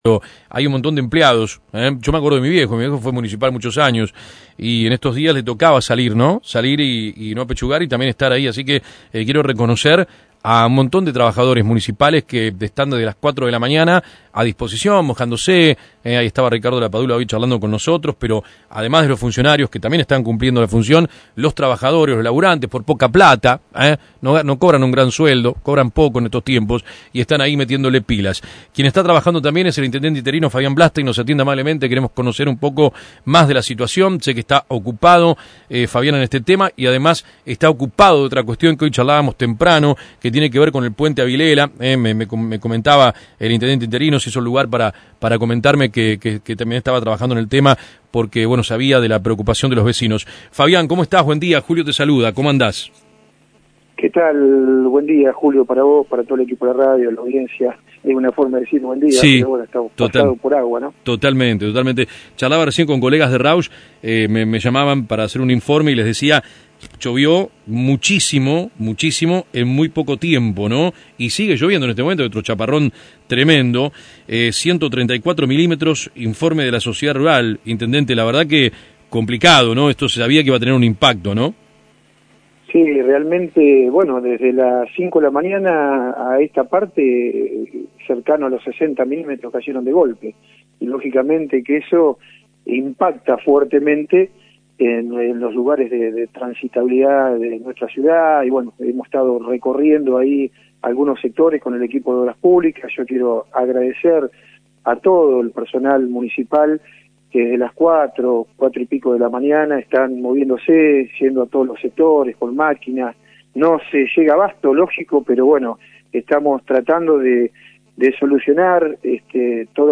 Dialogamos con Riccardo Lapadula, Secretario Obras Públicas y Fabián blansteín, Intendente Interino: